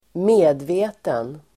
Uttal: [²m'e:dve:ten]